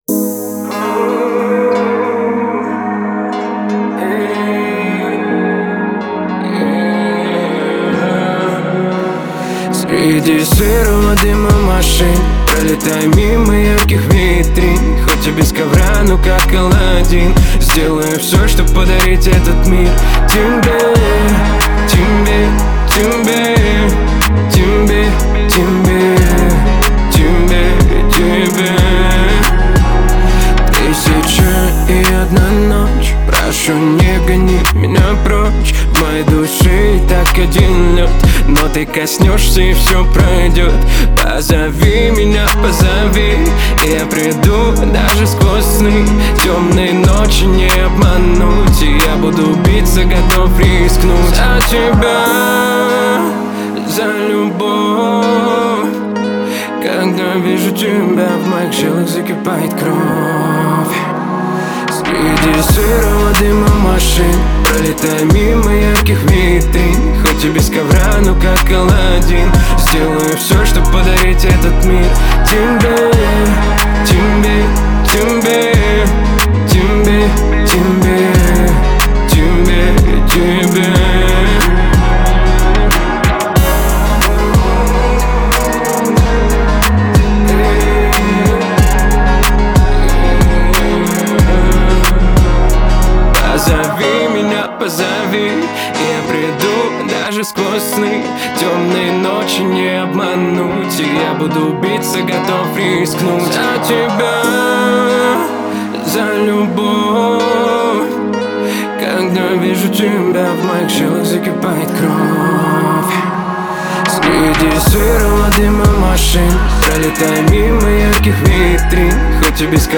это яркий пример поп-музыки с элементами восточной мелодики.